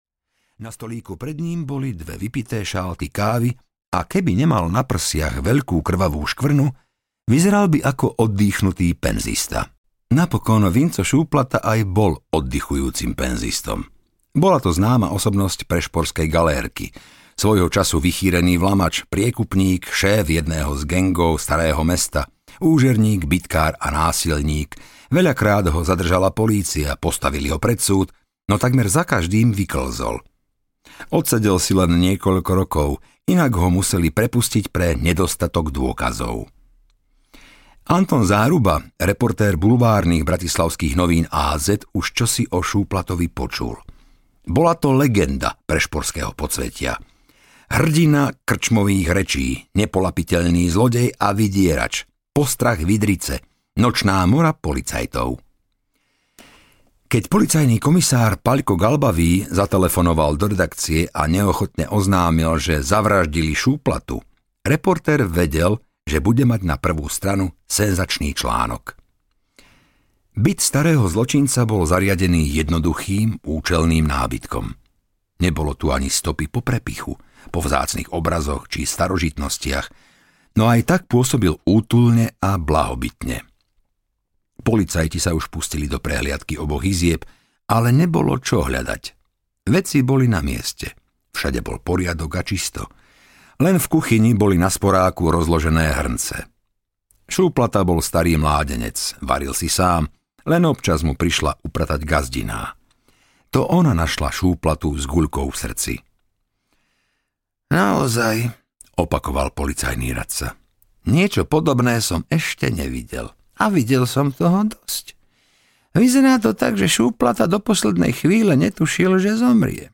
Červená sedma audiokniha
Ukázka z knihy